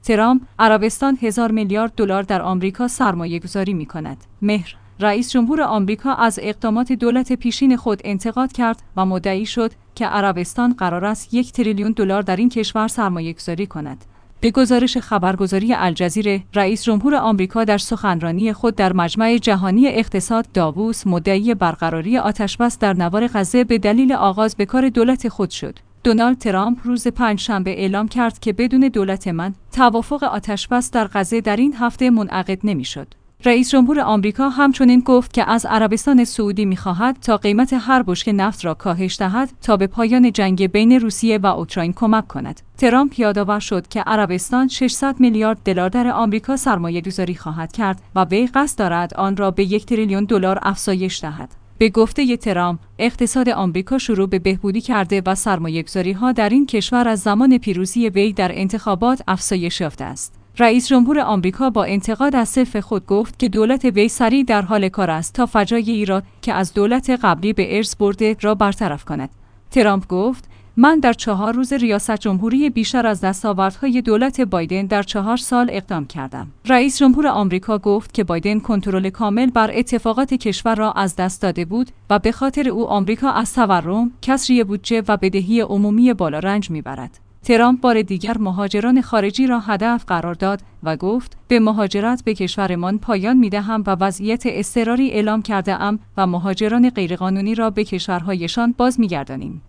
به گزارش خبرگزاری الجزیره، رئیس جمهور آمریکا در سخنرانی خود در مجمع جهانی اقتصاد (داووس) مدعی برقراری آتش بس در نوار غزه به دلیل آغاز به کار دولت خود شد.